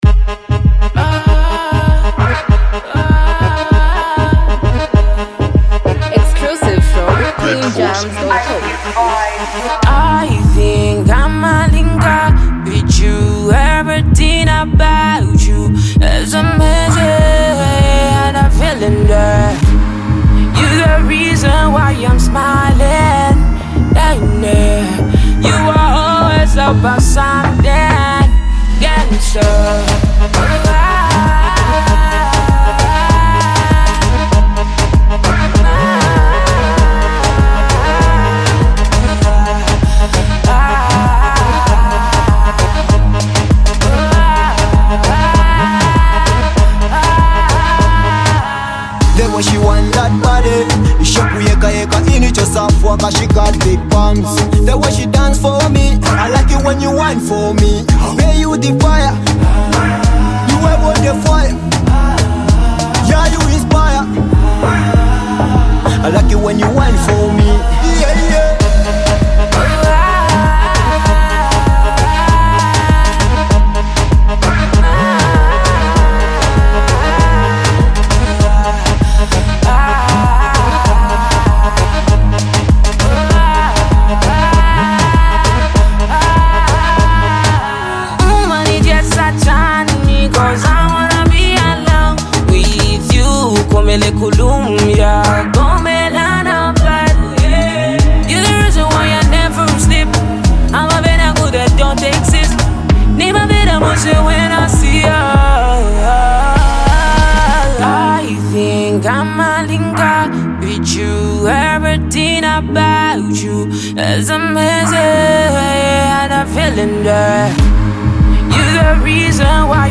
a bold anthem which is already trending county wide.